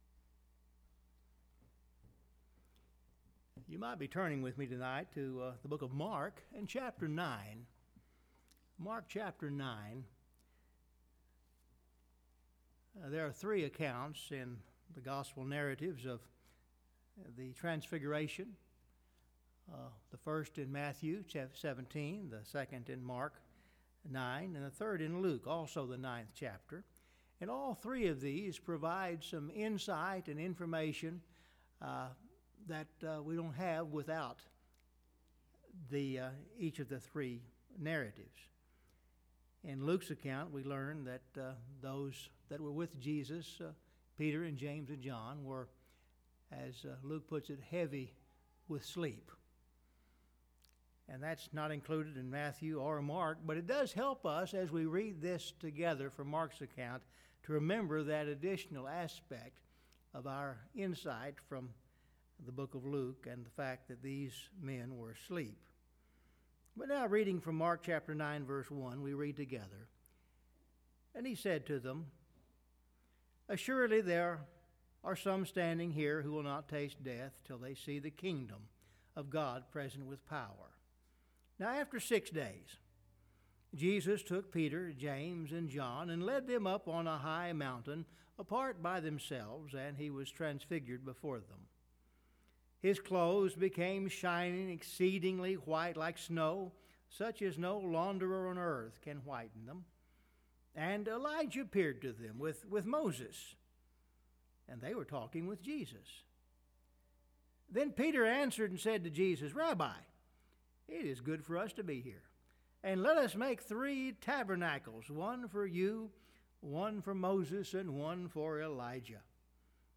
Scripture Reading – 2 Timothy 4:6-8